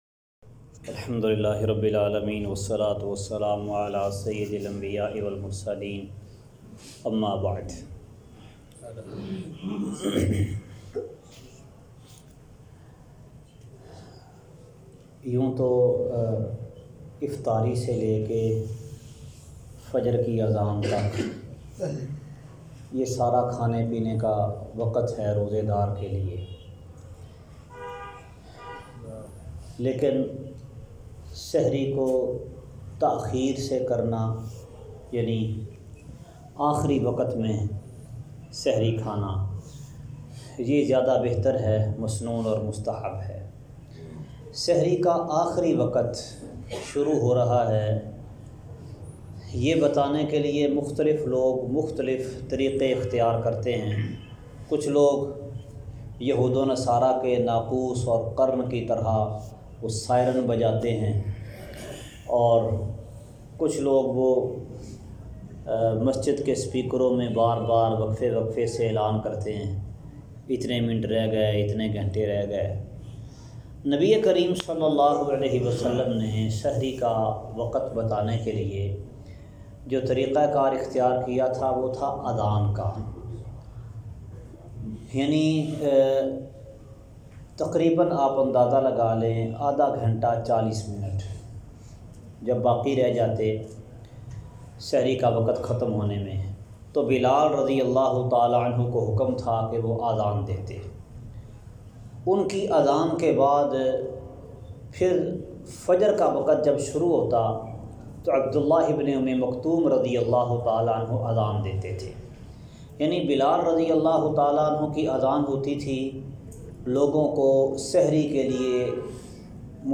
سحری کا وقت درس کا خلاصہ سحری کا وقت بتانے کا طریقہ اور آخری وقت۔